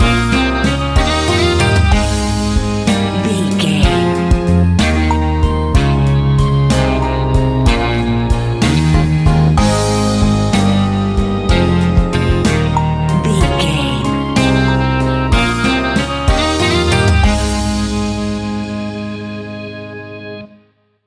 Uplifting
Ionian/Major
A♭
pop rock
indie pop
fun
energetic
acoustic guitars
drums
bass guitar
electric guitar
piano
organ